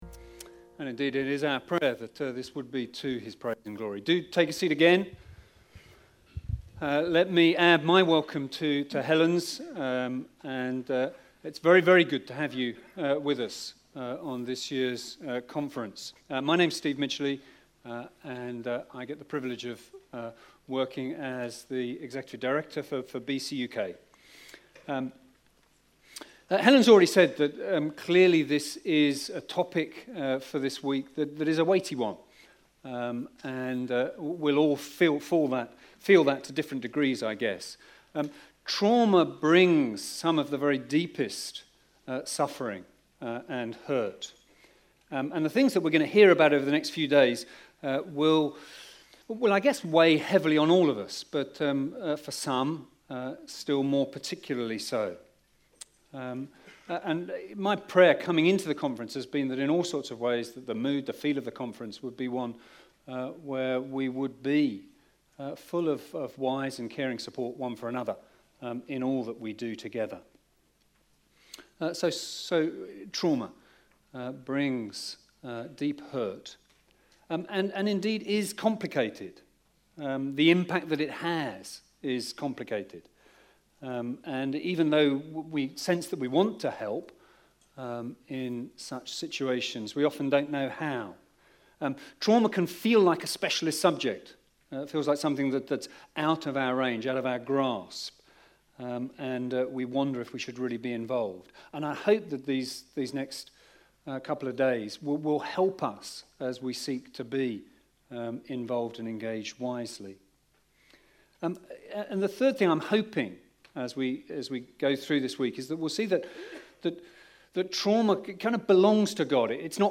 Session 1 from the 2025 annual conference - Trauma: Christ's comfort in deep suffering